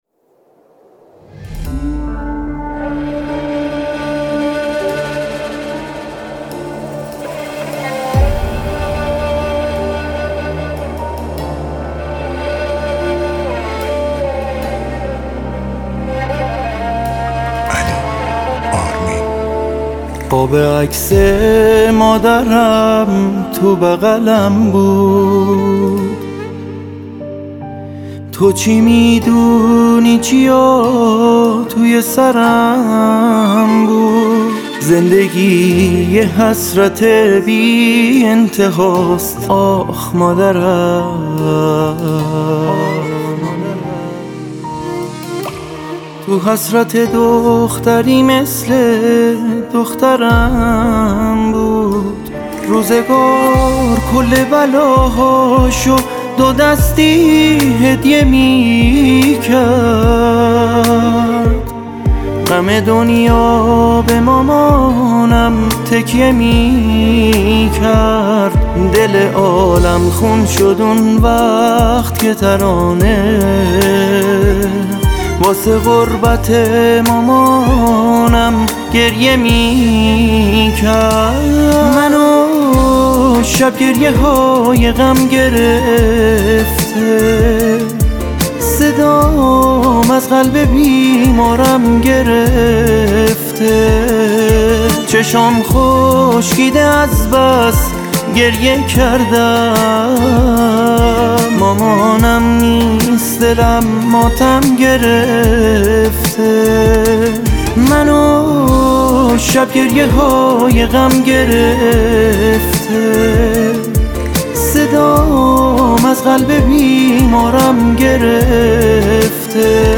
پاپ
آهنگ غمگین